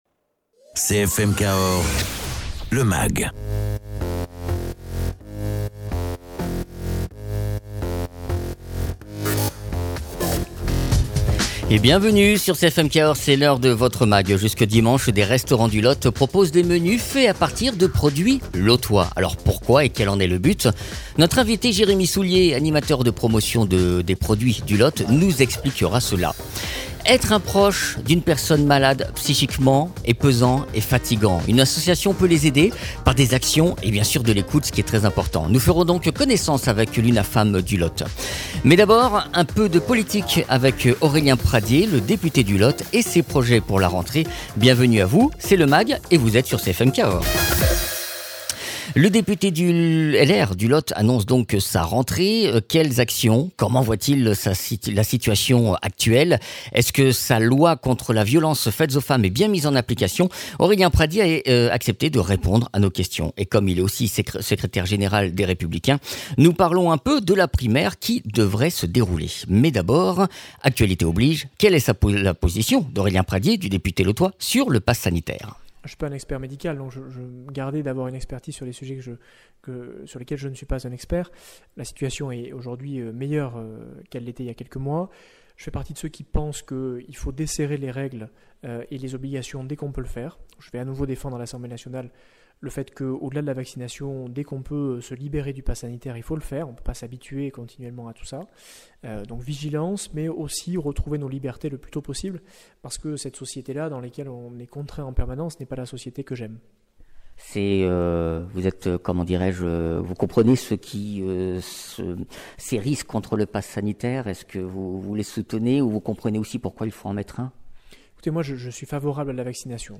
Aurélien Pradié, Député LR du Lot.